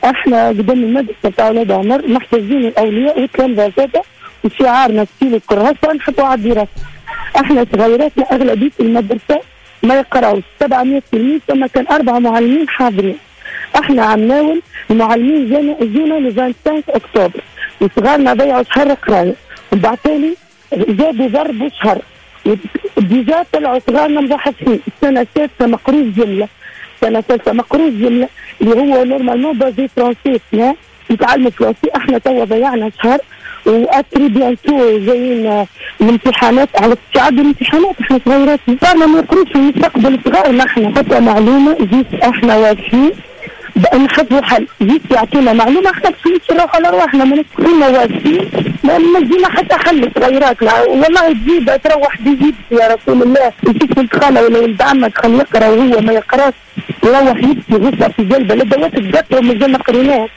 أحد الأولياء يتحدث لأوليس أف أم .(تسجيل).